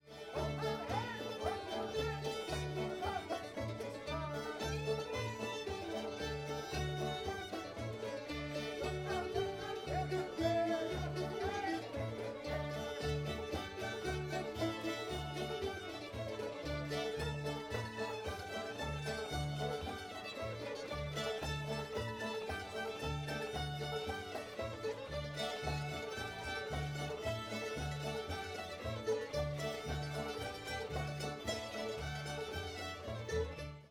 old molly hare [D]